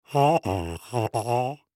Здесь собраны необычные звуковые композиции: от тонких природных мотивов до абстрактных эффектов, способных вызвать яркие ассоциации.
Звук, где человек осознал истину комедийный